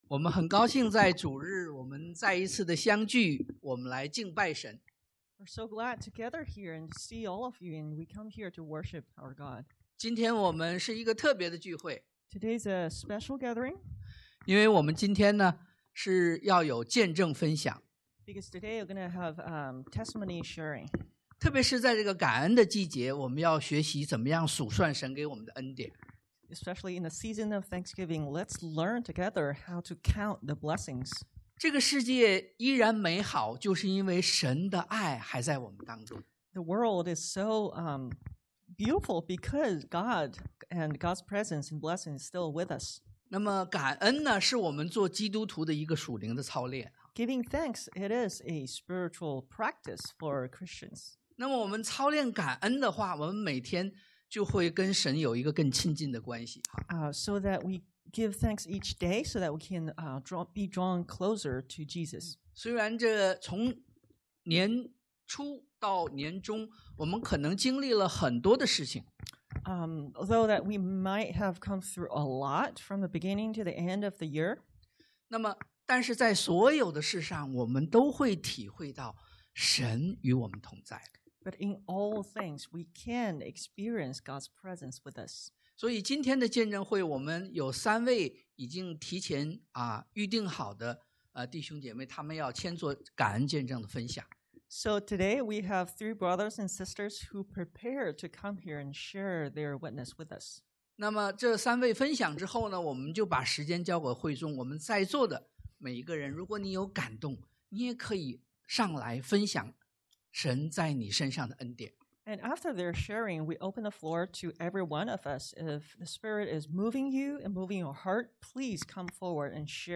2021-11-21 Thanksgiving Testimonies 感恩見證 – Central Coast Chinese Christian Church in San Luis Obispo
2021-11-21 Thanksgiving Testimonies 感恩見證